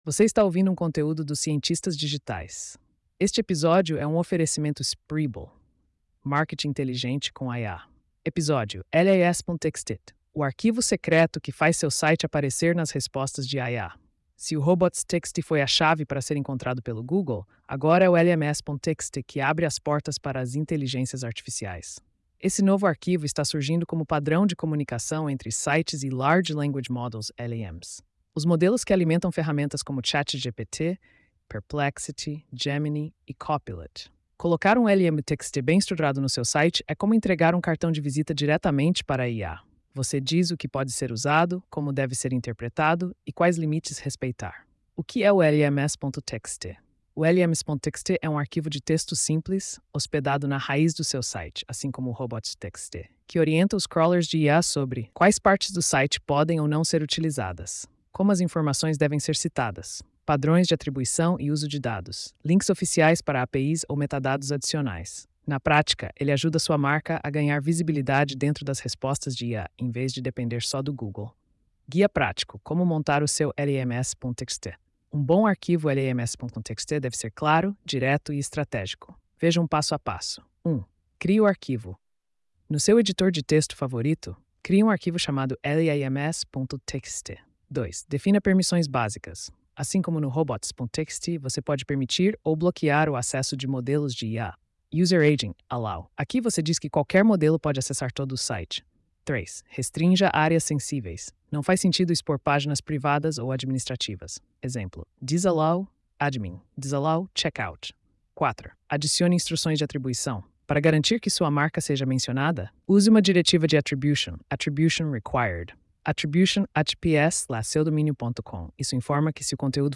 post-4266-tts.mp3